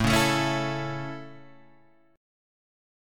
A chord {5 4 7 6 5 5} chord